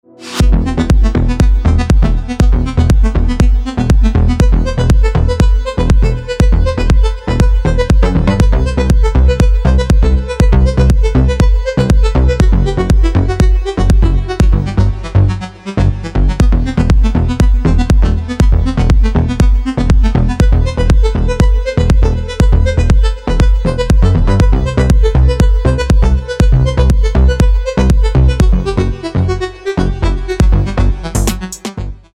• Качество: 320, Stereo
красивые
deep house
dance
без слов
club